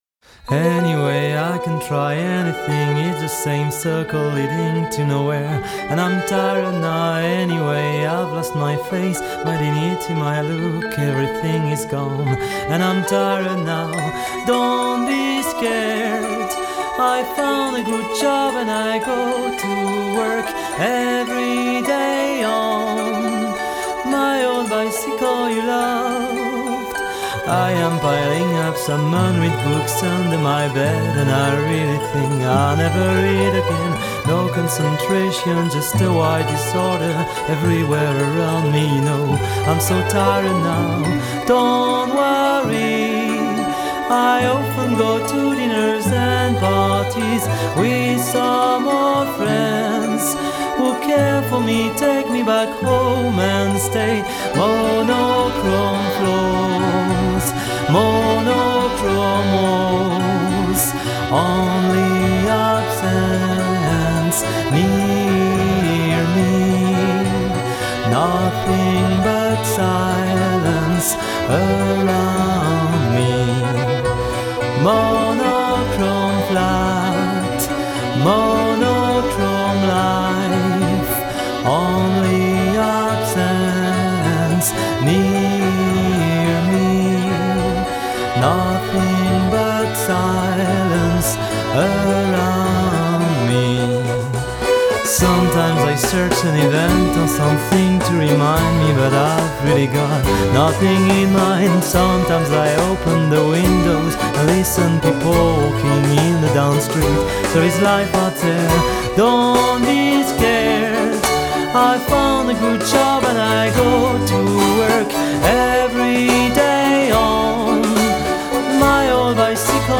Genre: World Music, Alternative, Neo-Classical, Neo-Folk